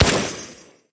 marig_fire_01.ogg